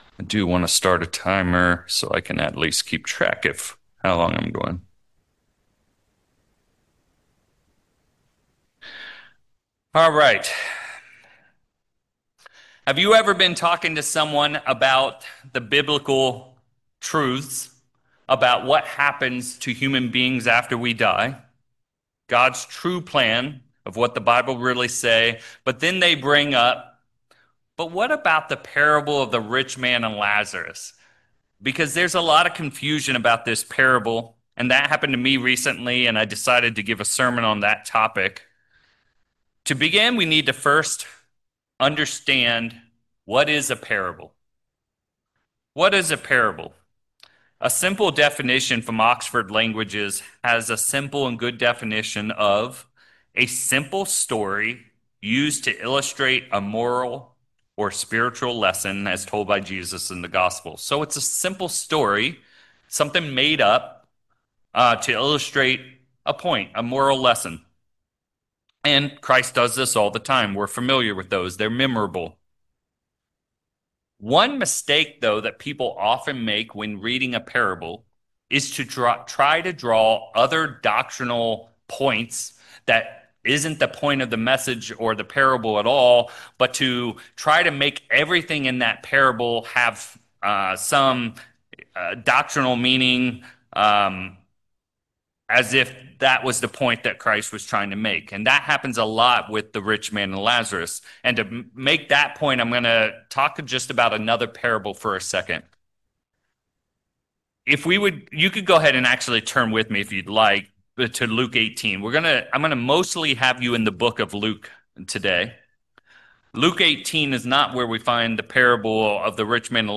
Sermons
Given in San Francisco Bay Area, CA Petaluma, CA San Jose, CA